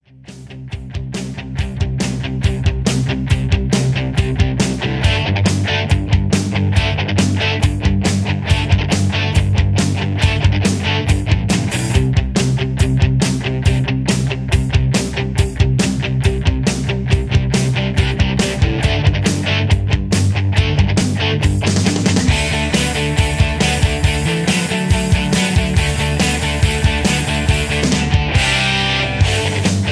(Key-G) Karaoke MP3 Backing Tracks
Just Plain & Simply "GREAT MUSIC" (No Lyrics).